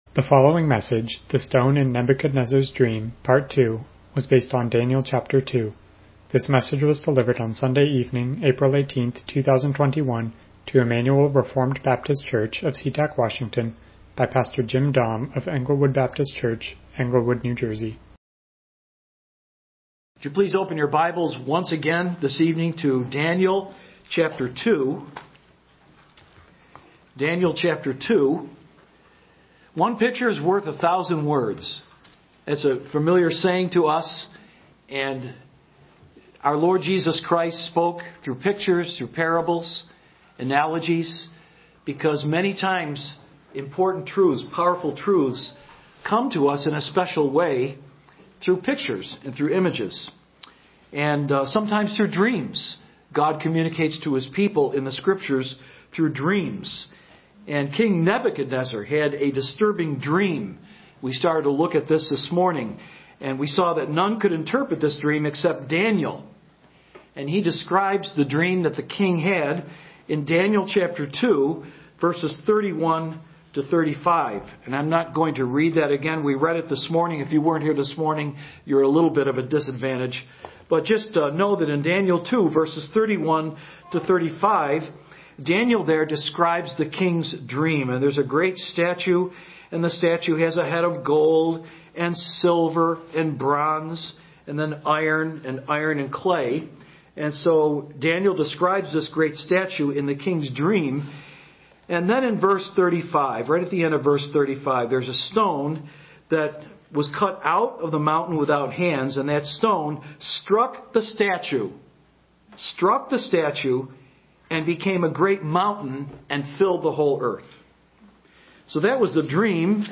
Daniel 2 Service Type: Evening Worship « The Stone in Nebuchadnezzar’s Dream